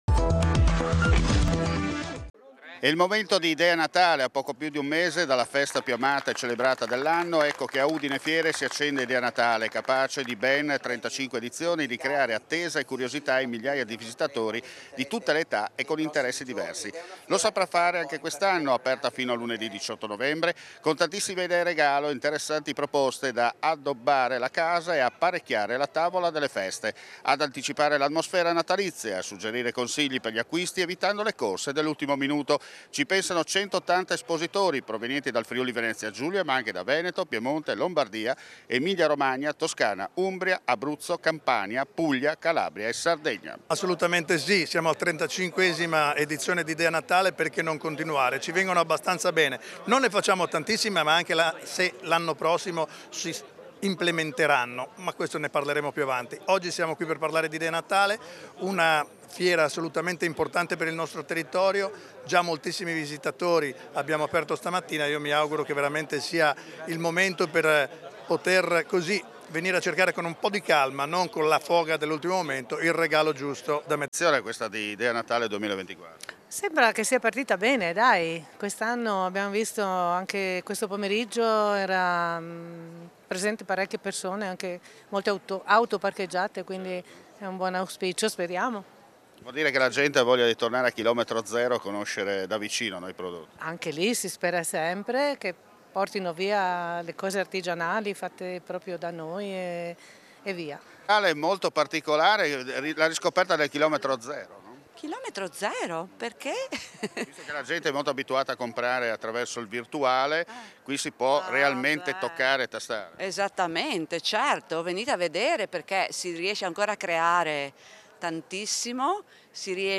MARTIGNACCO: Entusiasmo per Idea Natale 2024. Abbiamo raccolto la voce della gente
UDINE: Passa l’emendamento di Fratelli d’Italia su un contribuito per i Comune del Fvg in vista del Natale. Le parole di Markus Maurmair Consigliere Regionale Fdi.